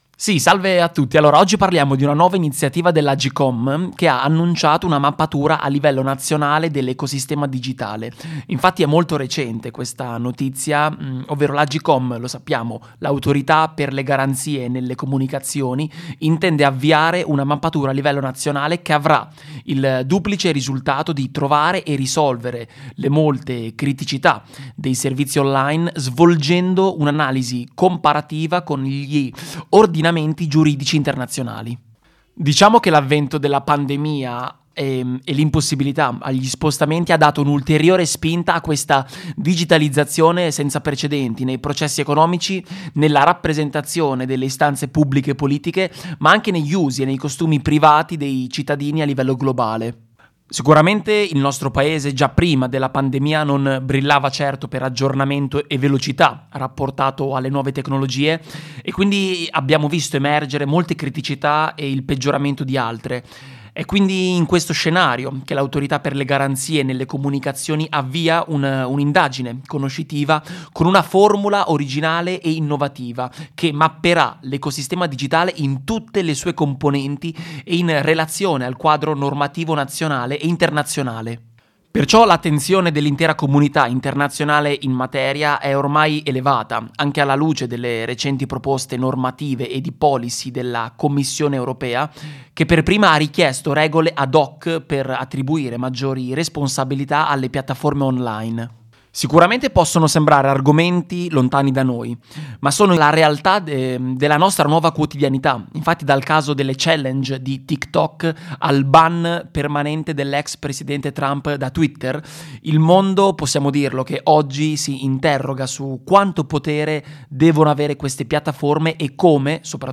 Iniziativa telematica del 16/02/2021 su Radio Sa
Interventi web radio